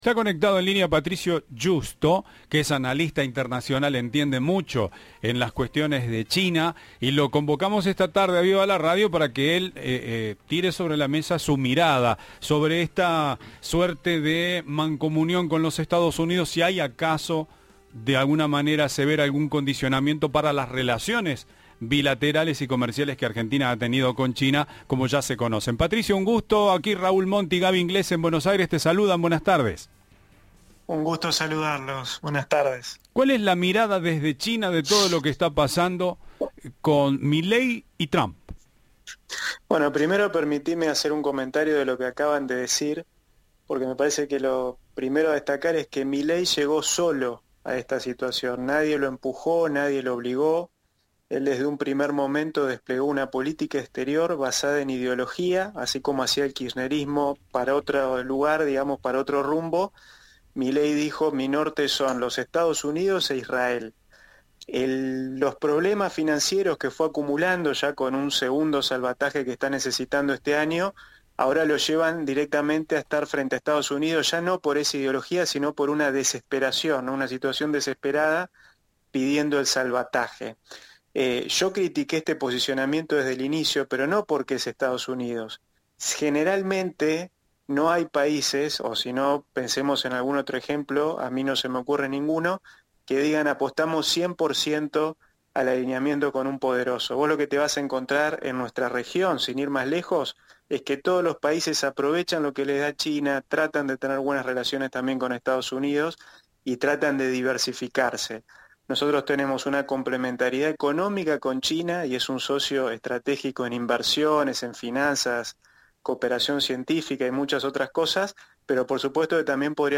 Entrevista de "Viva la Radio"